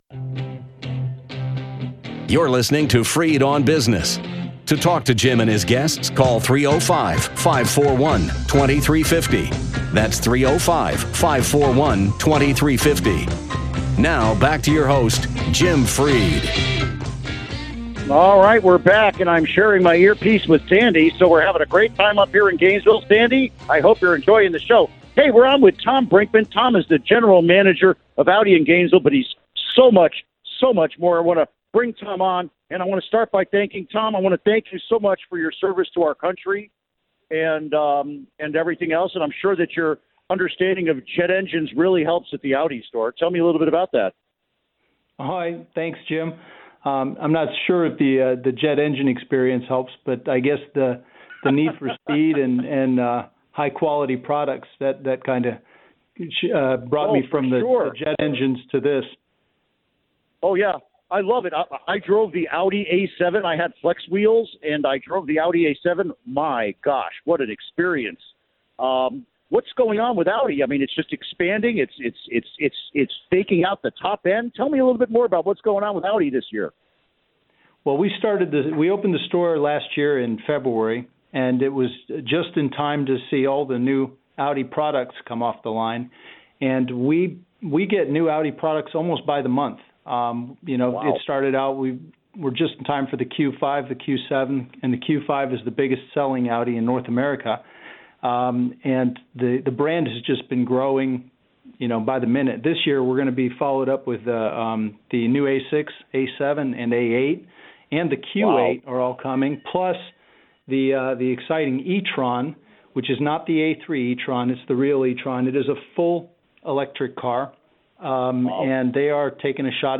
Interview Segment (To download, right-click and select “Save Link As”.)